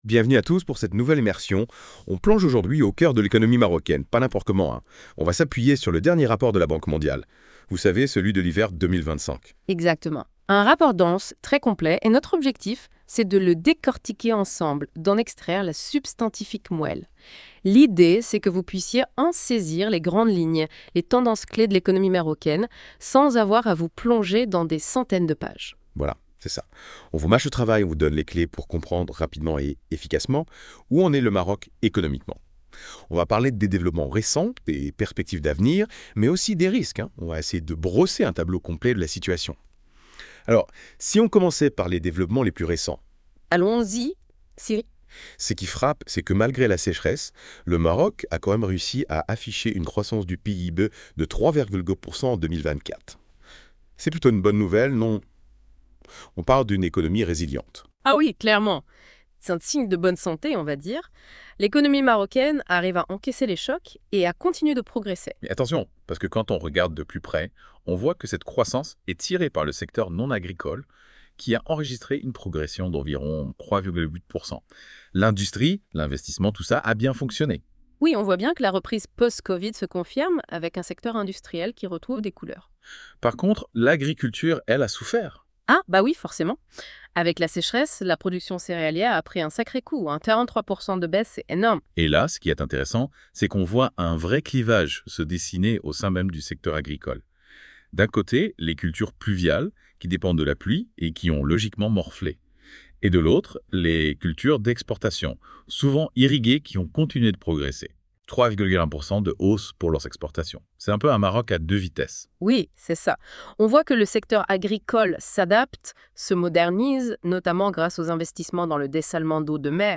+ Débat en podcast des chroniqueurs de la Web Radio R212 sur le contenu de ce rapport